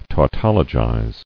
[tau·tol·o·gize]